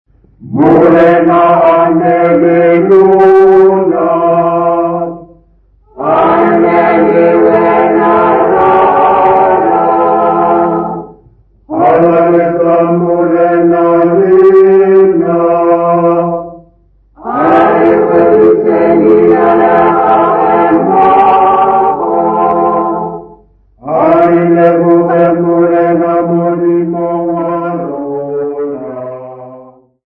Music workshop participants
Folk music
Sacred music
Field recordings
Church choral hymn with unaccompanied singing. Setswana church music composer's workshop.
96000Hz 24Bit Stereo